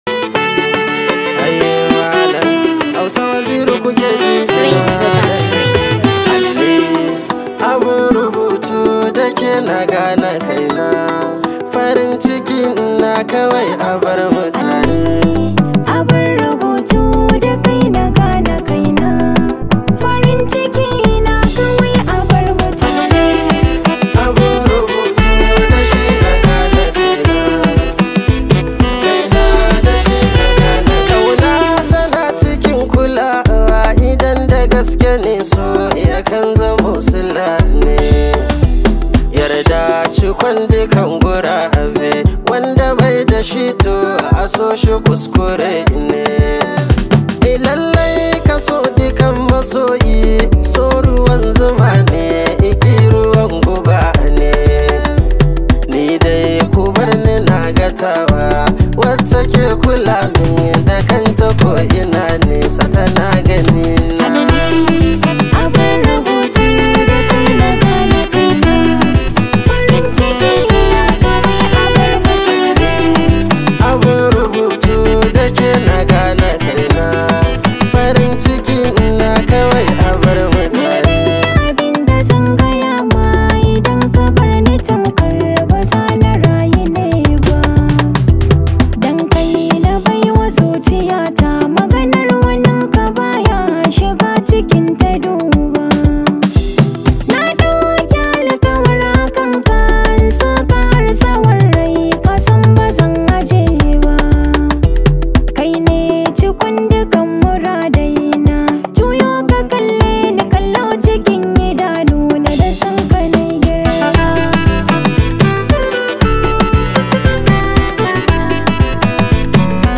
highly celebrated Hausa Singer
it comes with a lot of energy and positive Vibes